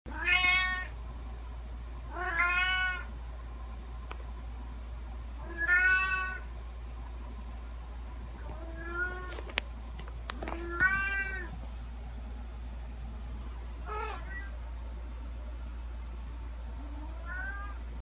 Cat Meow Meowing Bouton sonore